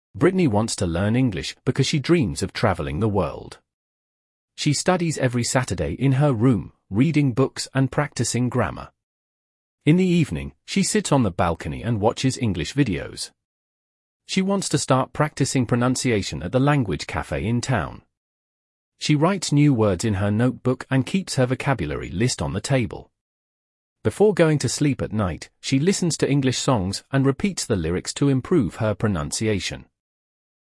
Listening Activity 1 - UK English.mp3